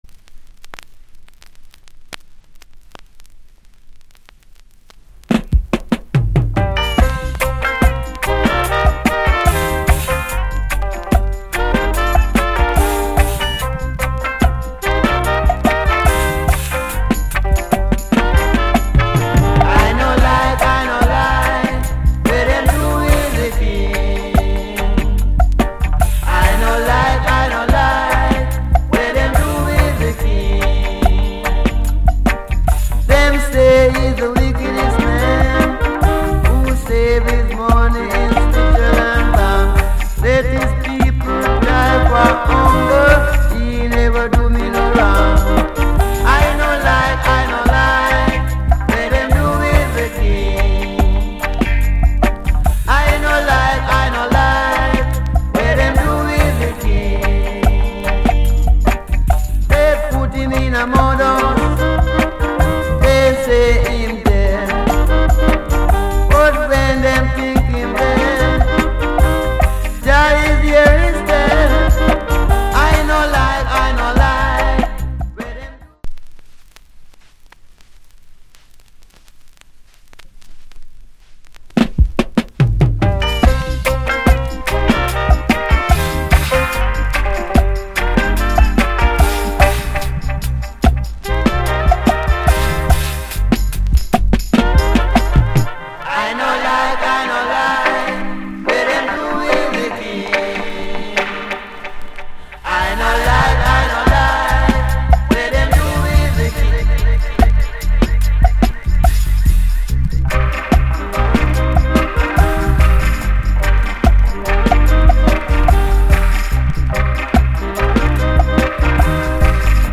Reggae70sLate / Male Vocal Condition EX- Soundclip